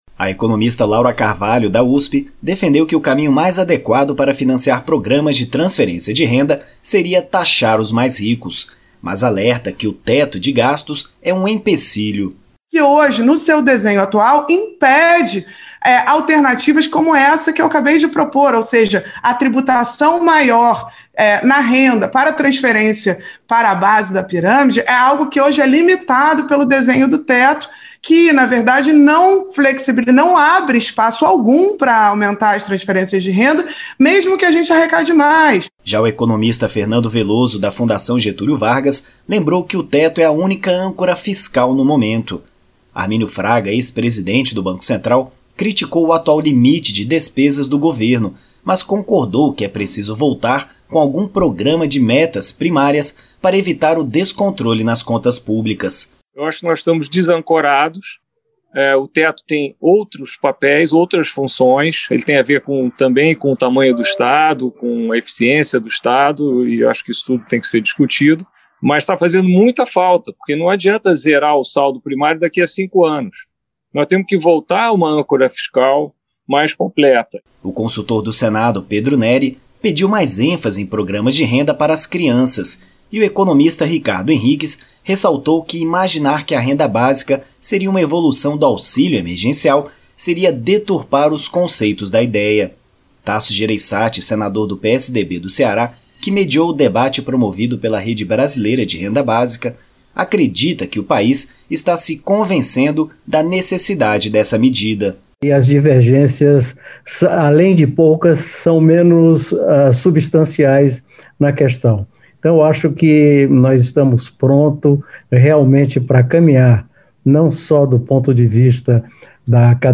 O senador Tasso Jereissati (PSDB-CE) disse nesta segunda-feira (9) que o país está se convencendo da necessidade de ter um programa de renda básica. Tasso mediou um debate promovido pela Rede Brasileira de Renda Básica, que reuniu economistas e parlamentares. A reportagem